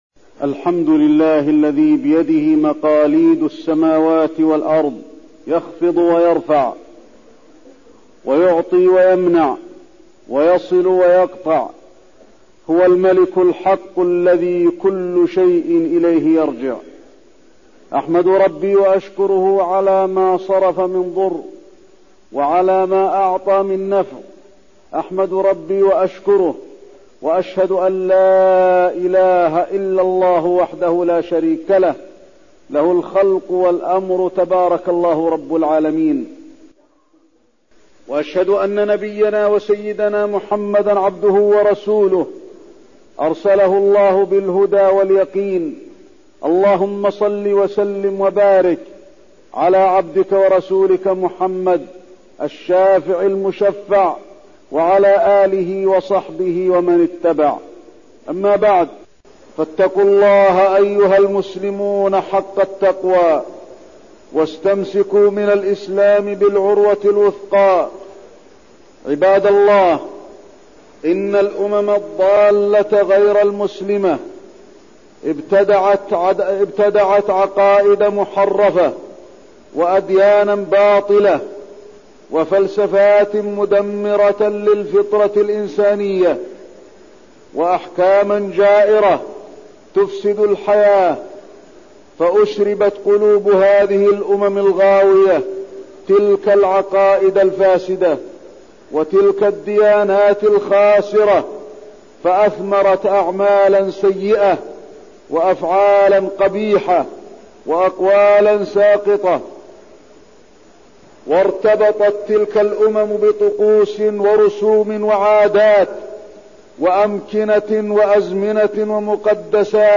تاريخ النشر ٢٠ ذو القعدة ١٤١٢ هـ المكان: المسجد النبوي الشيخ: فضيلة الشيخ د. علي بن عبدالرحمن الحذيفي فضيلة الشيخ د. علي بن عبدالرحمن الحذيفي نعمة الإسلام